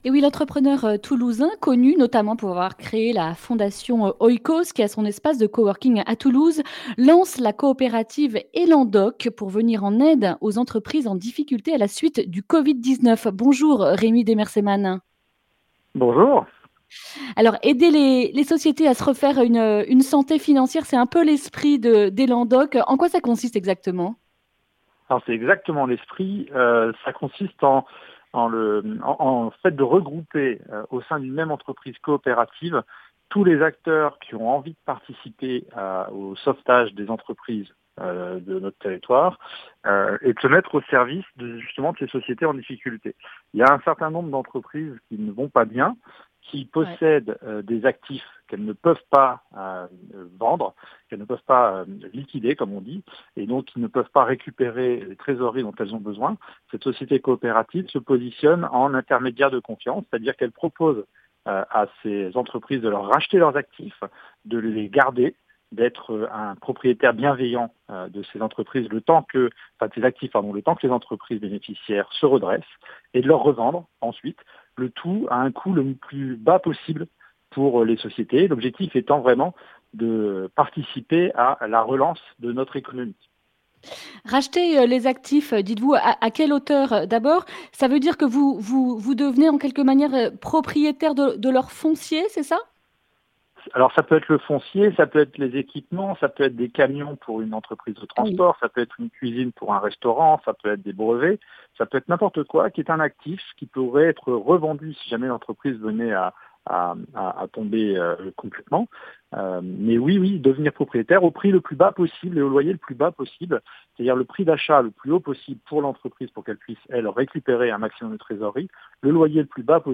lundi 12 octobre 2020 Le grand entretien Durée 10 min
Journaliste